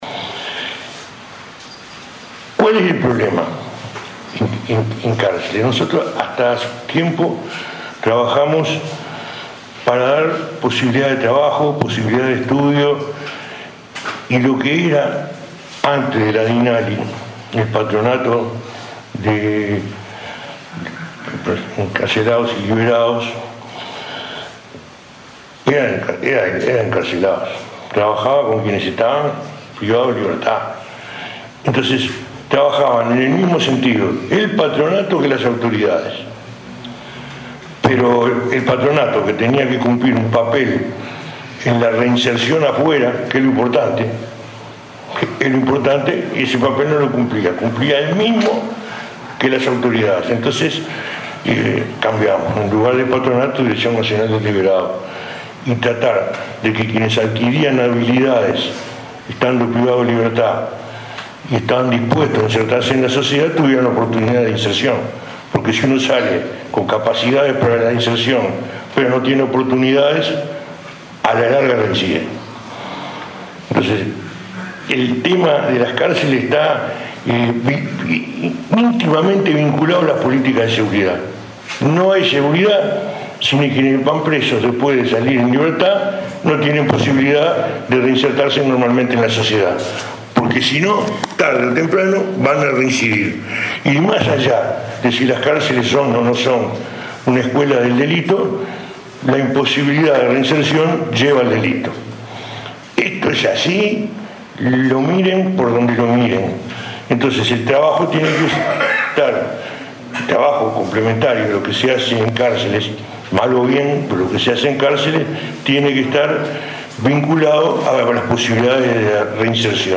El ministro Eduardo Bonomi advirtió que no hay seguridad si los que van presos, cuando salen, no tienen oportunidades de reinsertarse en la sociedad.
bonomi.mp3